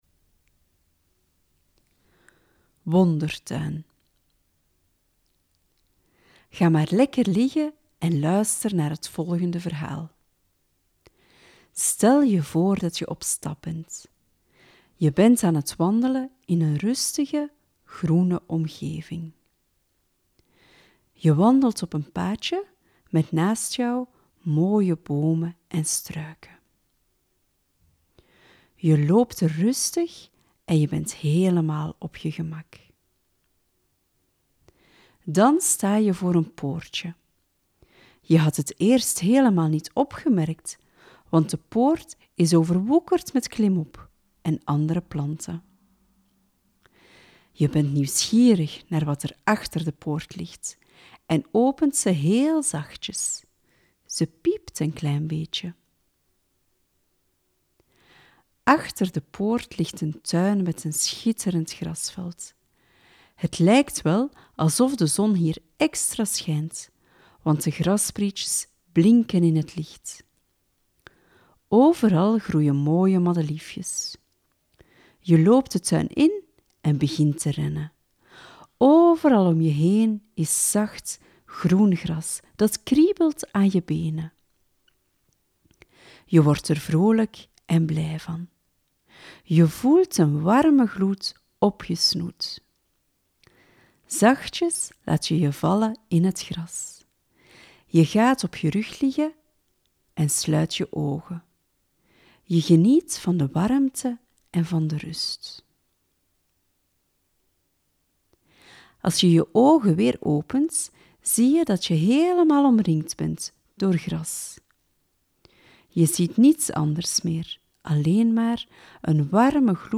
Wondertuin: Een kindvriendelijke visualisatie-oefening, waarbij je kind de talenten van zichzelf en van anderen kan bevestigen.